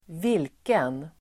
Uttal: [²v'il:ken]